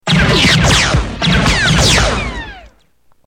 star-wars-boba-fett-blaster_24927.mp3